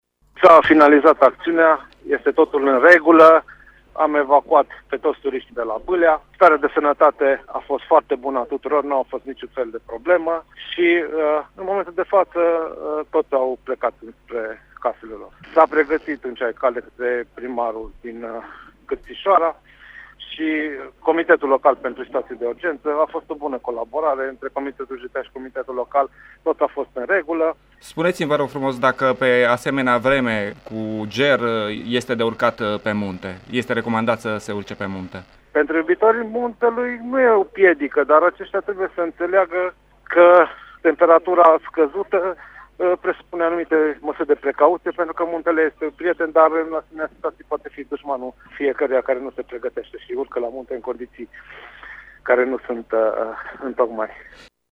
La rândul său, prefectul de Sibiu, Ioan Sitterli ne-a declarat, în urmă cu câteva minute, că operațiunea de intervenție s-a finalizat cu succes: